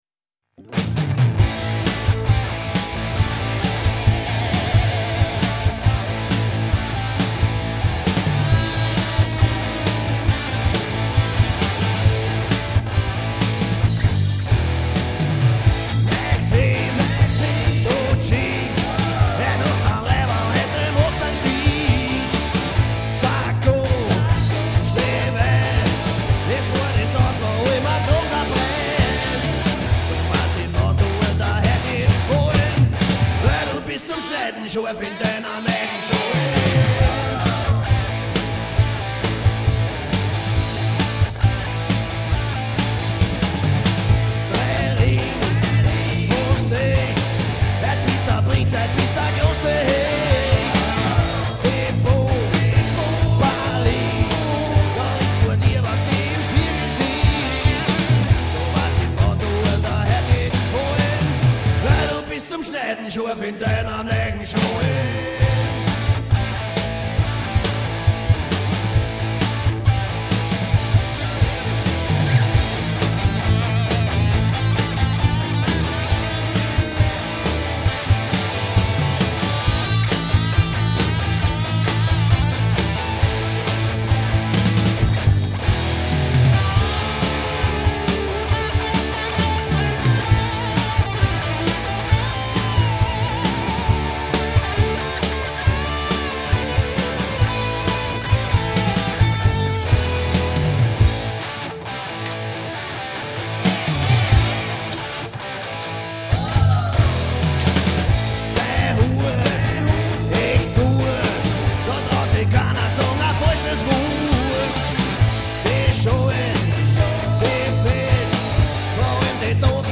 Lead Vocal, Guitar
Bass, Back Vocals
Drums, Back Vocals
Demo Songs